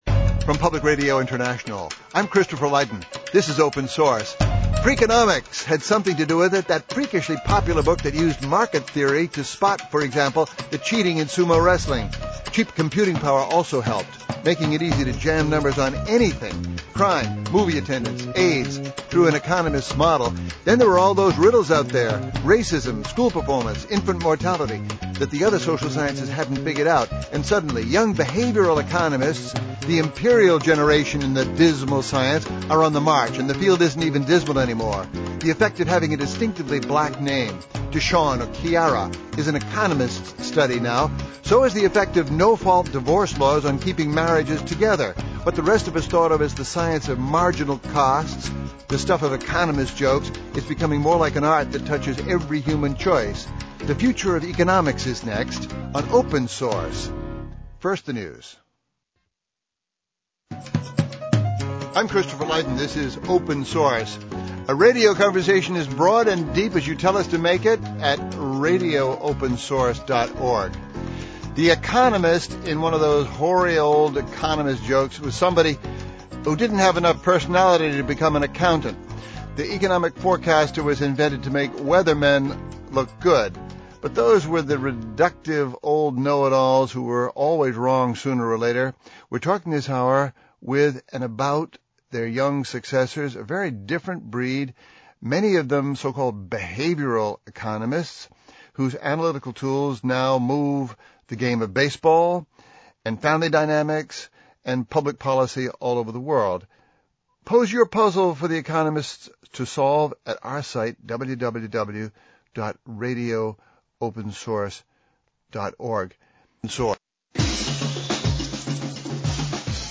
Radio: Economics Reimagined ( Full 1 hour program , mp3 ) Radio Open Source with Christopher Lydon, Public Radio International, January 11, 2007. Radio discussion about the future of economics.